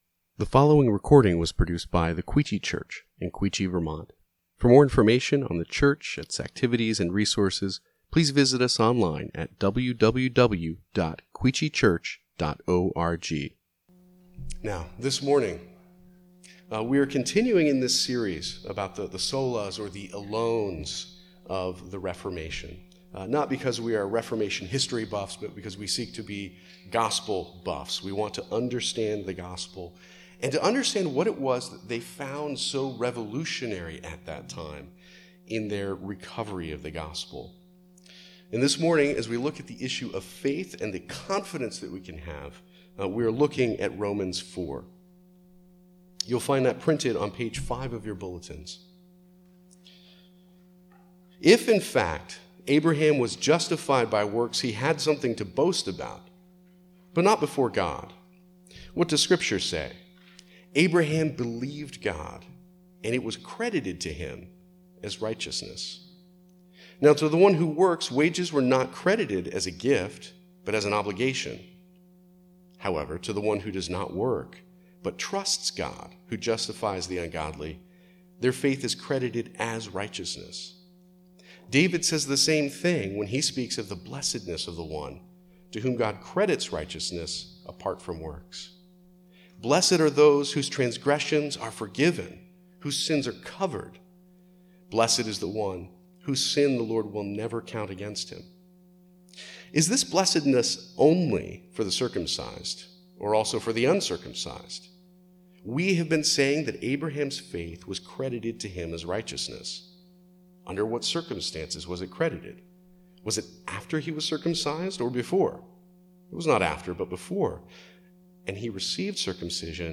Quechee Church | Sermon Categories Romans